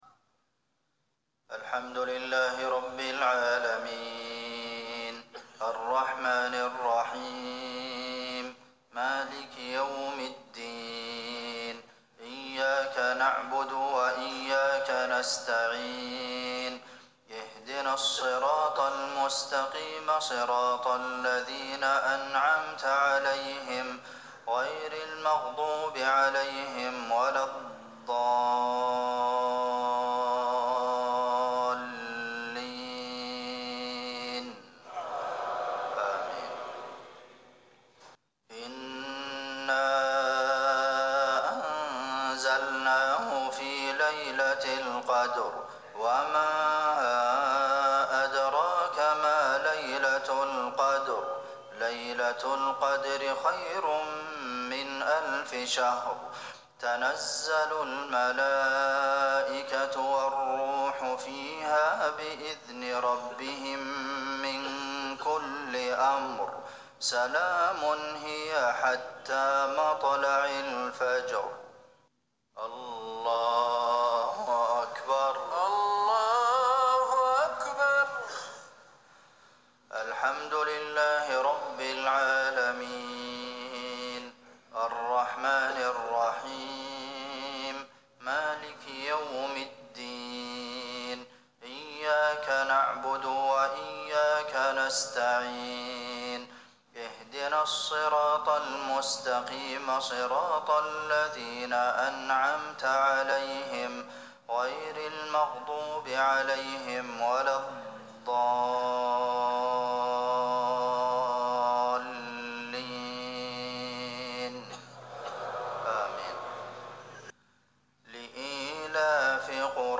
مغرب 3 شعبان 1440هـ  سورتي القدر و قريش | Maghrib prayer Surah Al-Qadr and Quraysh 8- 4- 2019 > 1440 🕌 > الفروض - تلاوات الحرمين